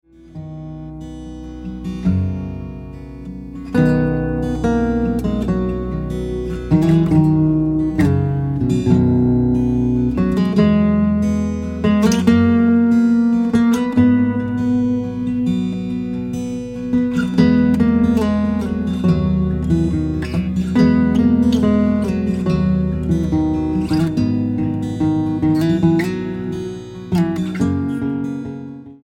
STYLE: Pop
instrumental renditions of carols
a pleasant and relaxing collection